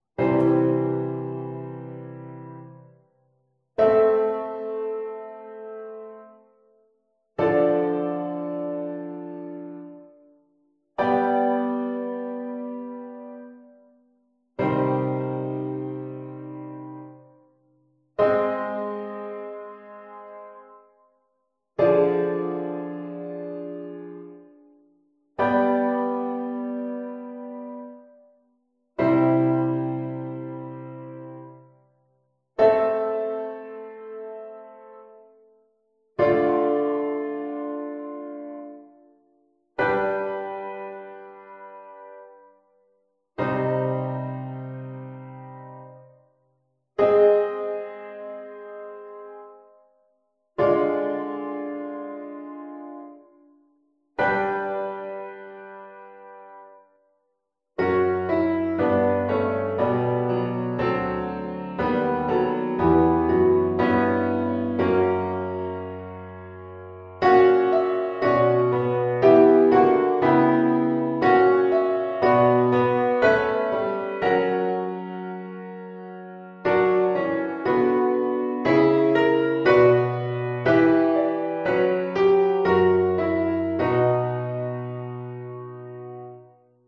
Hieronder (voorbeeld 10) het begin van een koraalmelodie, in twee zettingen met te grote afstanden tussen de stemmen, en in een 'pianozetting' (nauwe ligging met 'hoge tenor').
te grote afstand tussen stemmen